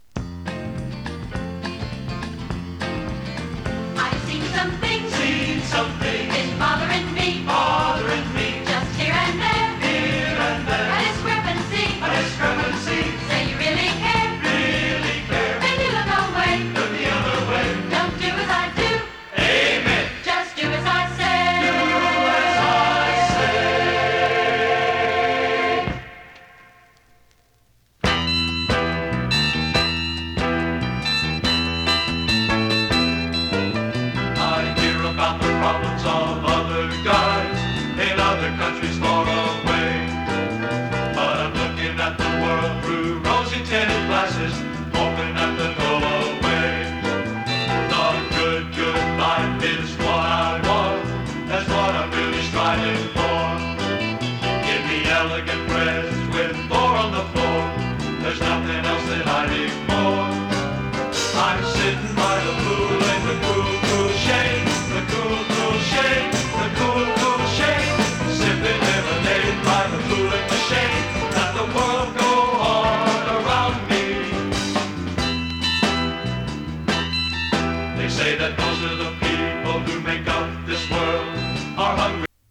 ＊稀に軽いチリ/パチ・ノイズ。